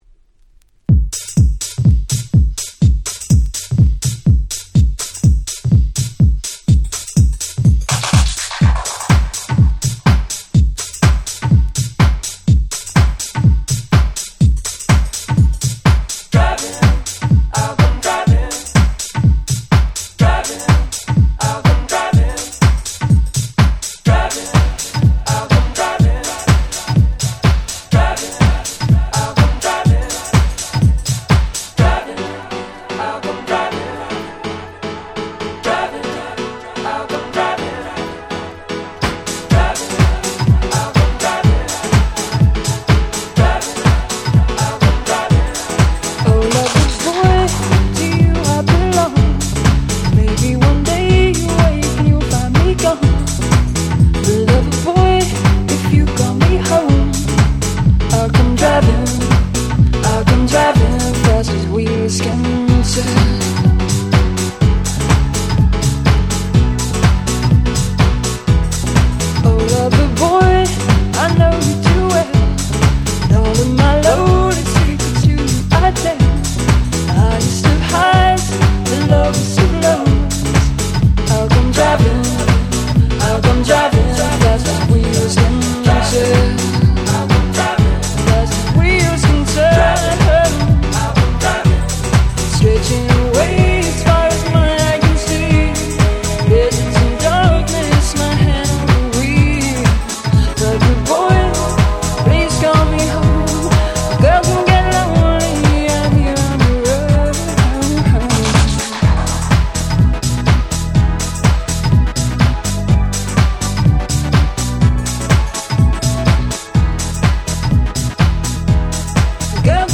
96’ Nice UK R&B / Vocal House !!